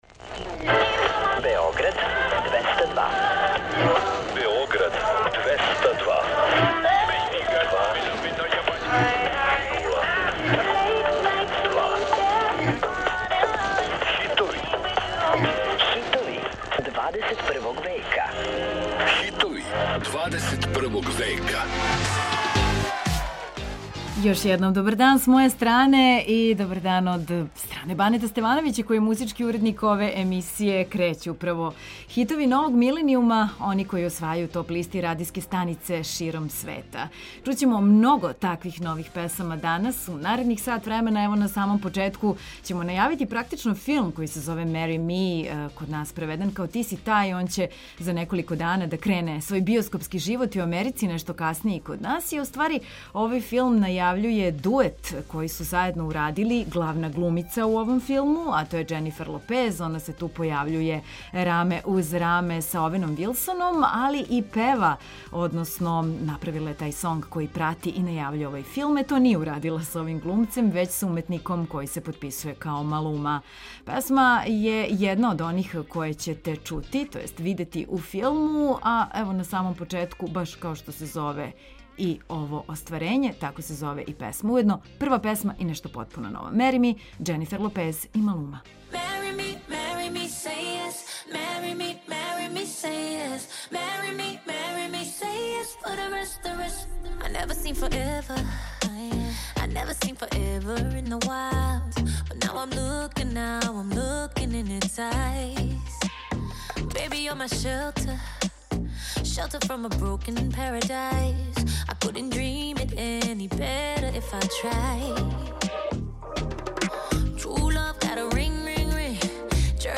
Slušamo hitove novog milenijuma, koji osvajaju top liste i radijske stanice širom sveta.